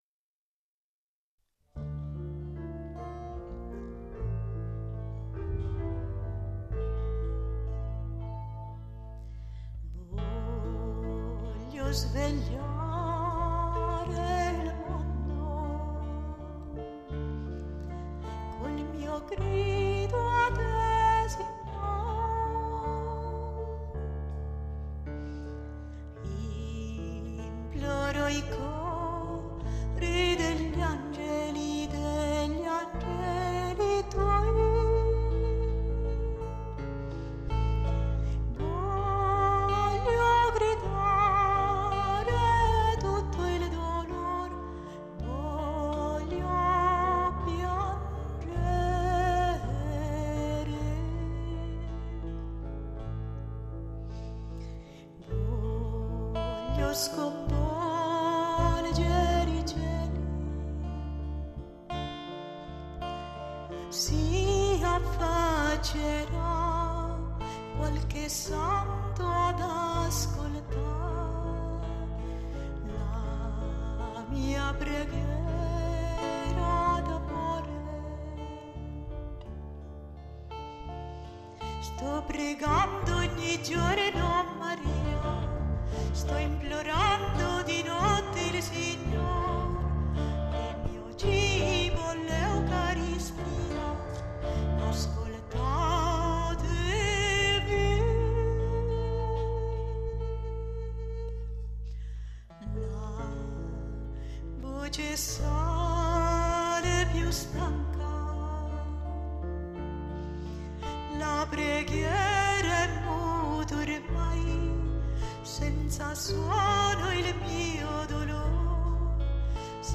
Preghiera del misero canto mp3